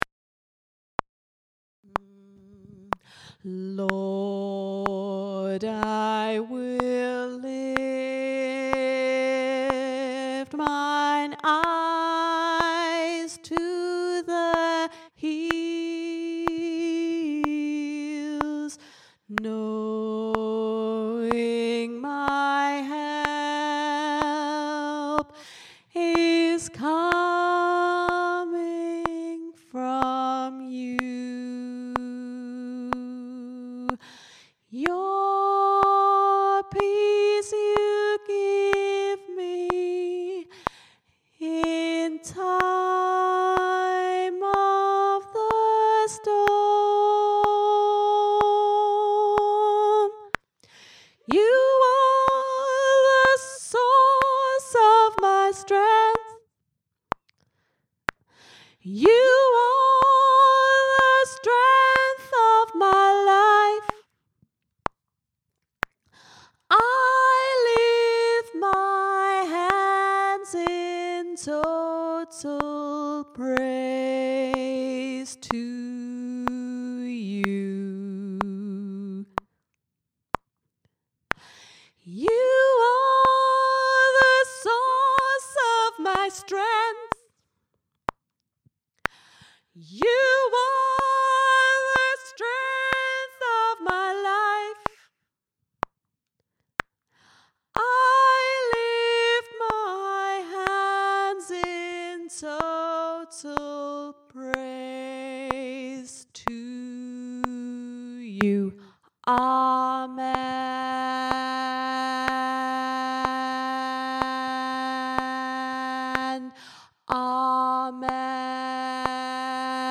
total-praise-alto.mp3